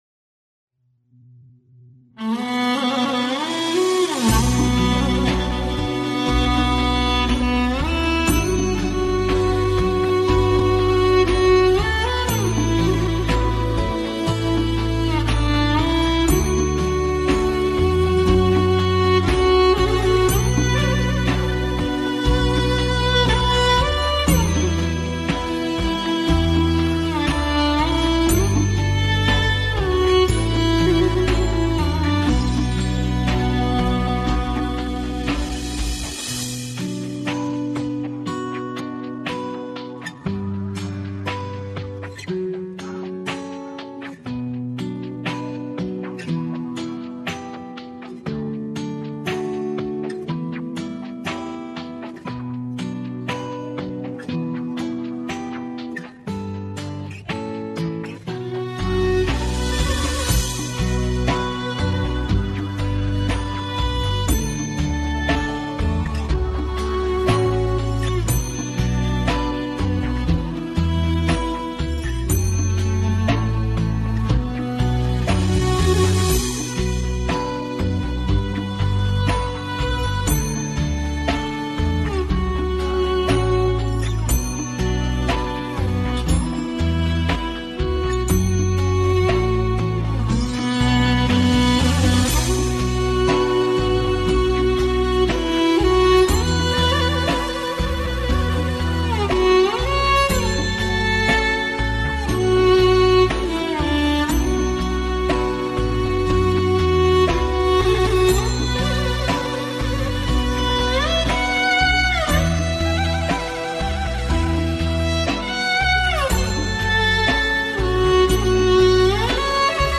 无 调式 : D 曲类